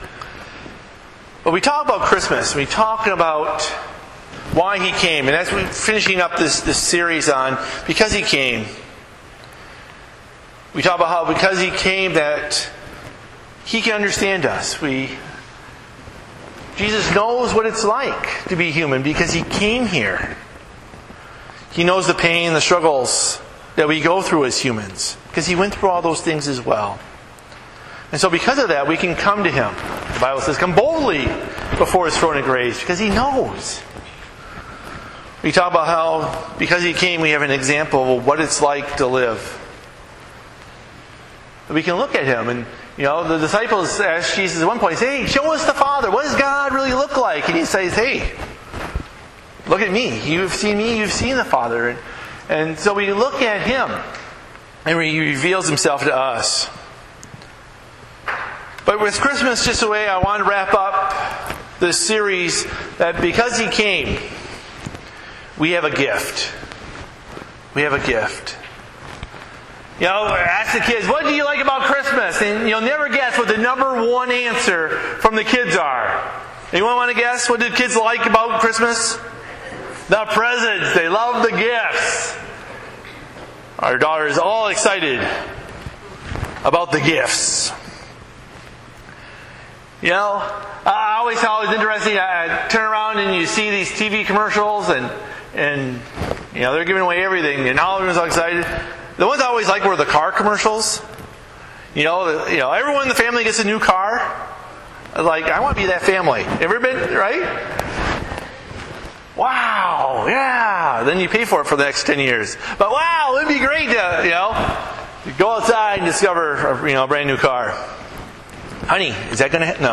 Bible Text: Ephesians 2:8-9 | Preacher